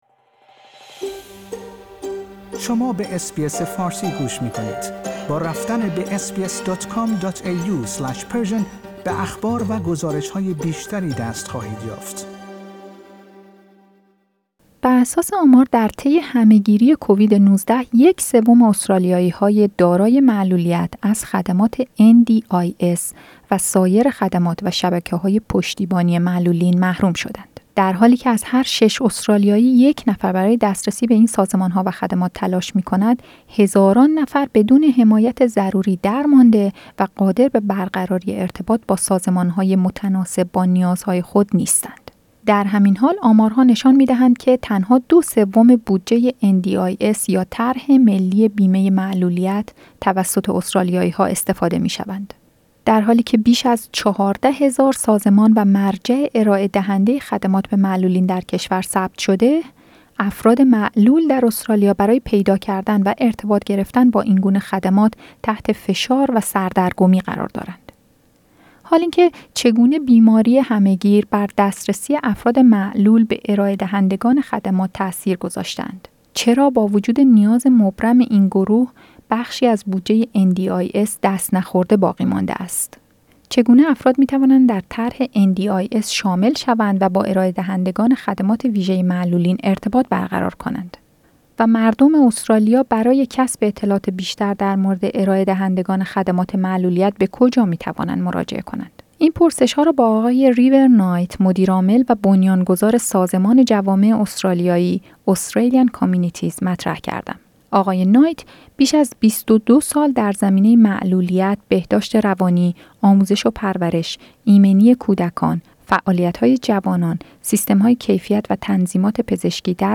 بر اساس آمار، در طی همه گیری کووید-۱۹ یک سوم استرالیایی های دارای معلولیت از خدمات NDIS و سایر خدمات و شبکه های پشتیبانی معلولین محروم شدند. گفتگوی اختصاصی